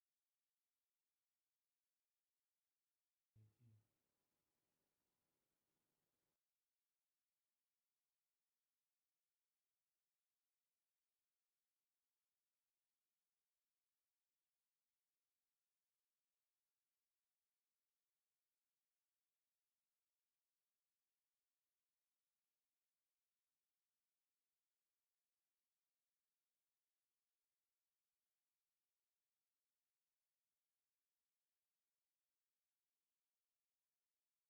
Pouring water over the WP56’s massive speaker and the droplets are literally shaking with the sound! 🔊💦 This phone doesn’t just play loud—it sounds loud, even with water on the speaker, thanks to its powerful 128dB output and IP68/IP69K waterproof rating.